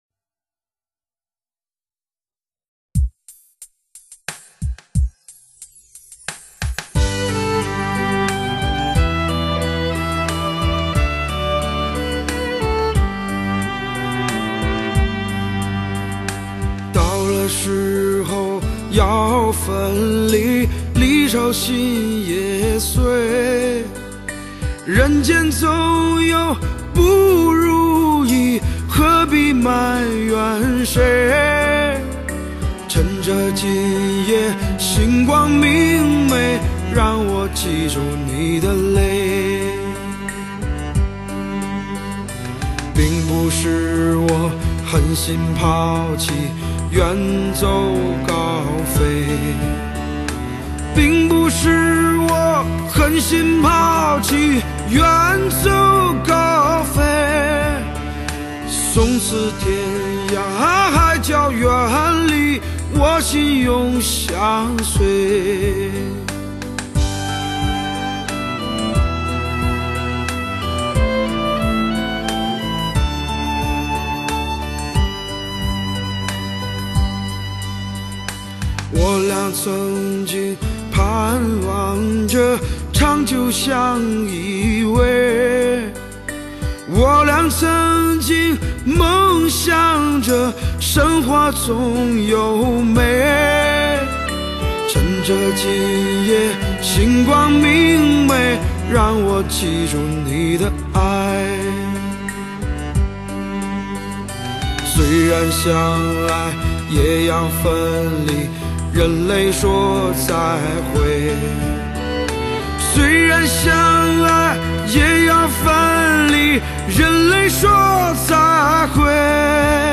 Hi-Fi 人声发烧碟